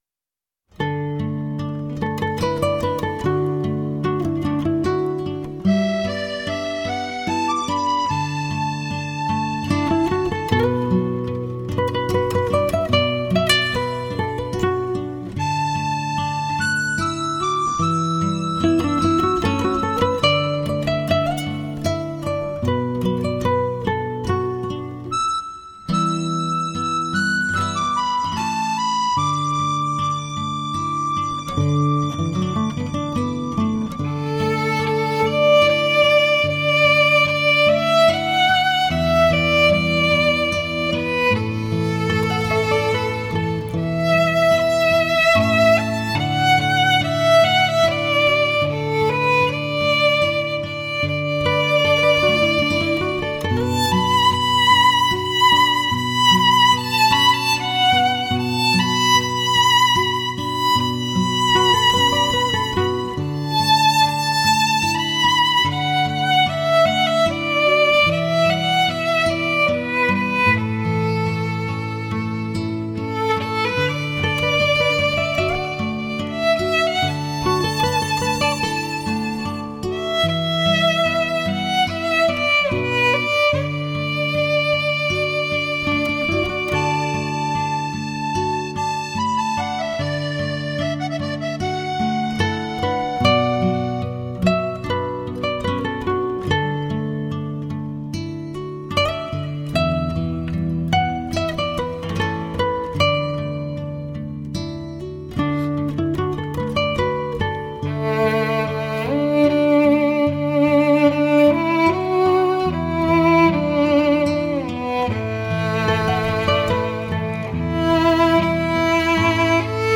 小提琴演奏
音色自然，香气直沁心脾。音质甜美，忧伤动人的旋律，炫技的演出，小提琴声线如泣如诉，扣人心弦，令人满怀舒畅，心神愉悦。